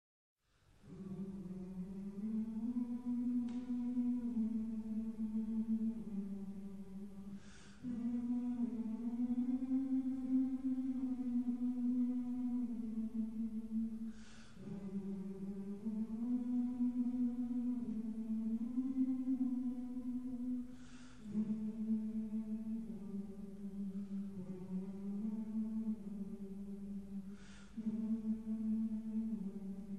솔 플레이트가 1923년에 부름
원래 코사어로 된 한 개의 절로 구성되었으며, 찬송가로 불릴 예정이었다.[3] B-플랫 장조로 쓰여졌으며, "서양 찬송가 작곡과 남아프리카 원주민 멜로디"의 특징을 모두 가진 반복적인 멜로디를 지원하는 4부 하모니를 갖추고 있었다.[4] 이 곡은 1899년 존 므보웨니를 목사로 임명할 때 처음 공개적으로 불렸다.[3]